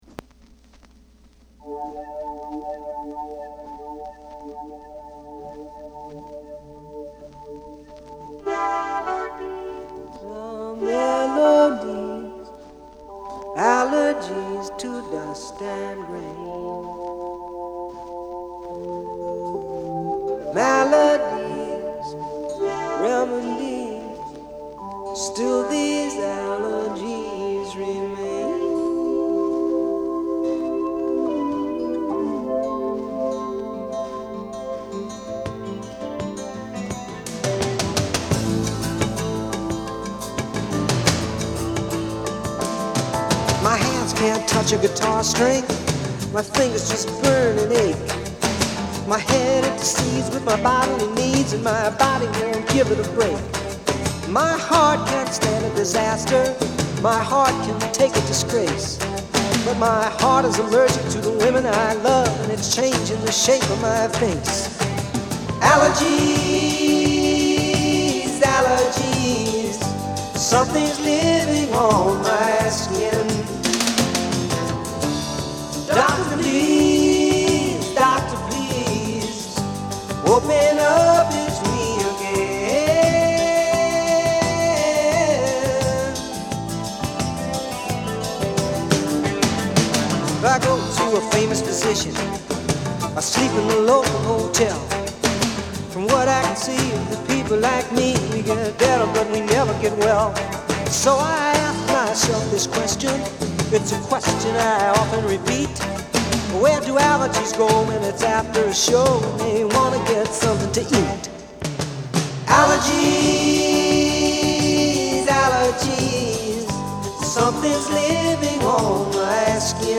POP / ROCK
盤は薄い擦れや僅かですが音に影響がある傷がいくつかある、使用感が感じられる状態です。